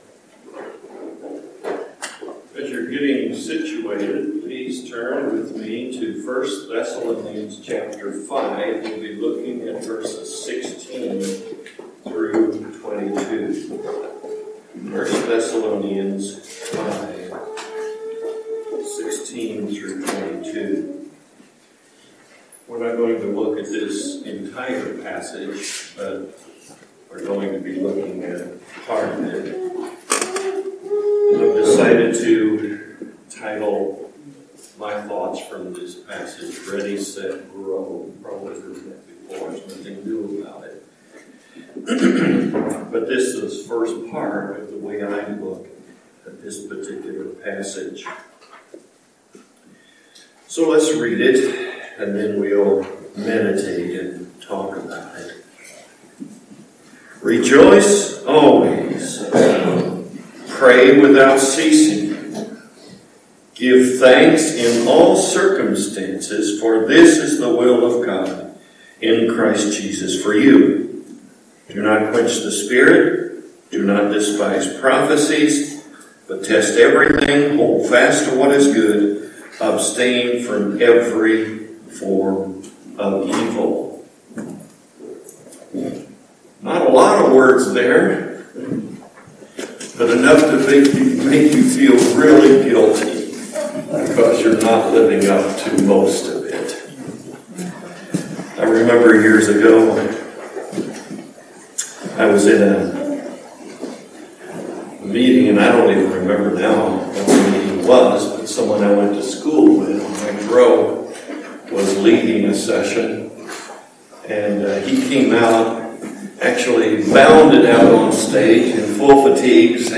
1 Thessalonians Passage: 1 Thessalonians 5:15-17 Service Type: Morning Worship « Why Did My Savior Come to Earth?